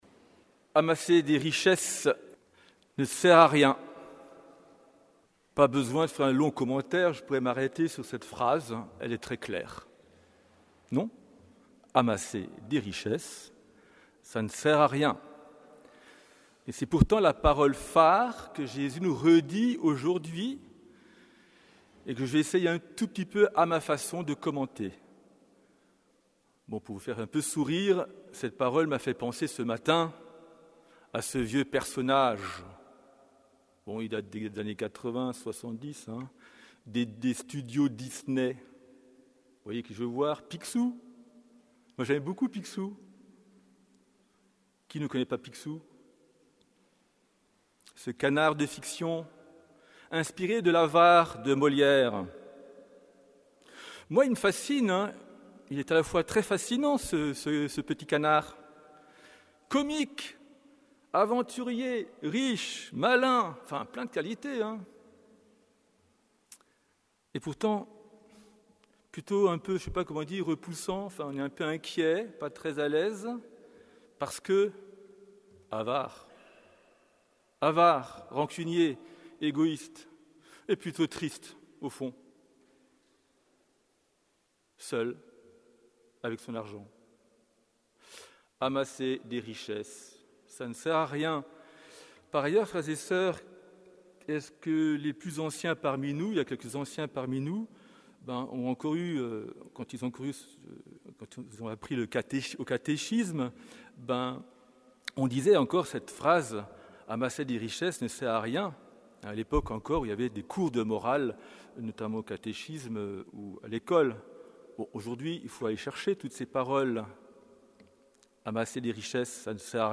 Homélie du 18e dimanche du Temps Ordinaire